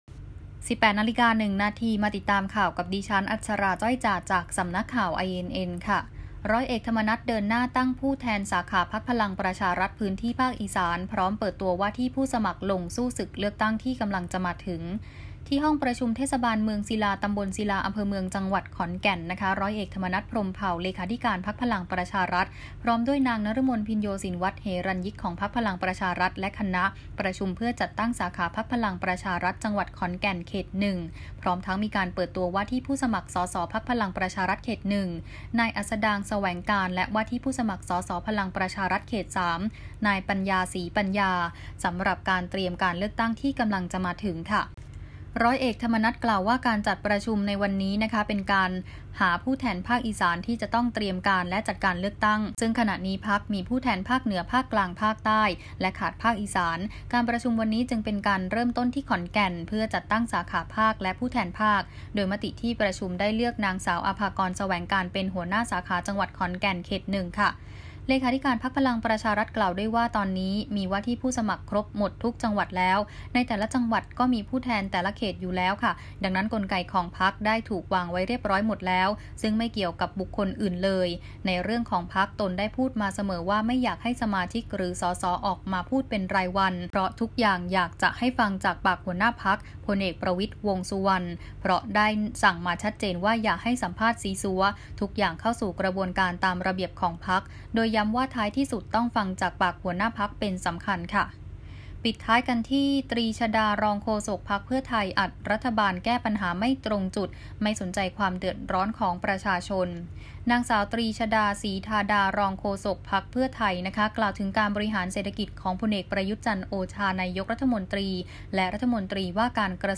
ข่าวต้นชั่วโมง 18.00 น.